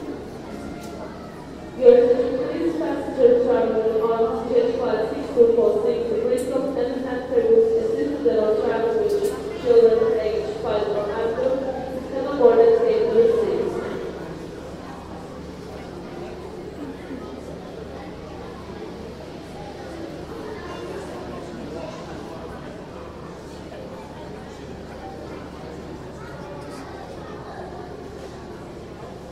Окунитесь в атмосферу аэропорта с коллекцией звуков объявлений и фоновых шумов.
Звуки в зале ожидания